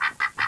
DUCKS.WAV